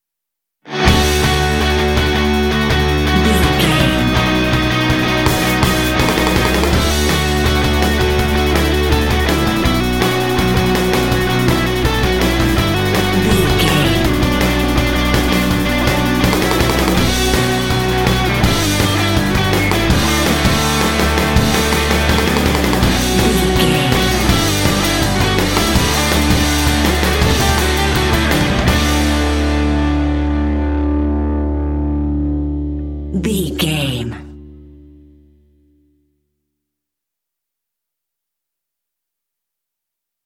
Ionian/Major
D
Fast
driving
energetic
lively
electric guitar
drums
bass guitar
classic rock
alternative rock